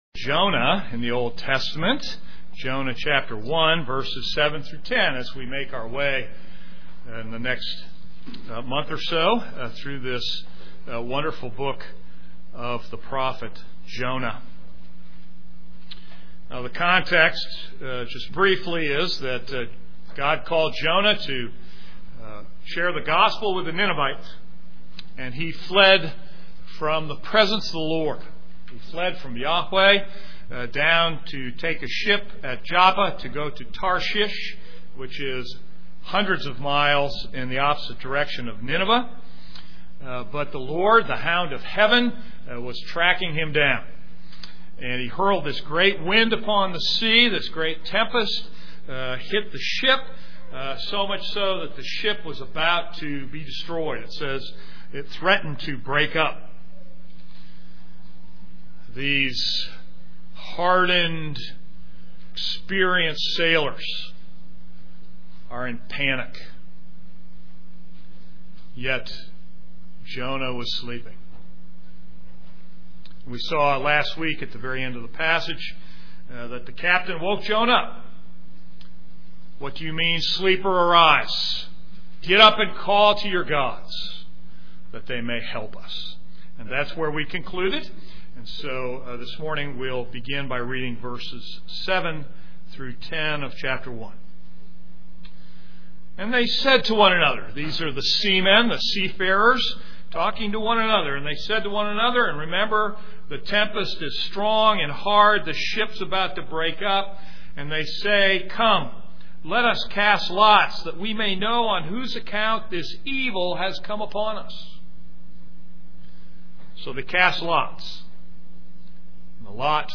This is a sermon on Jonah 1:7-10.